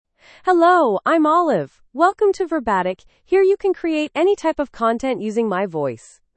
Olive — Female English (United States) AI Voice | TTS, Voice Cloning & Video | Verbatik AI
Olive is a female AI voice for English (United States).
Voice sample
Listen to Olive's female English voice.
Olive delivers clear pronunciation with authentic United States English intonation, making your content sound professionally produced.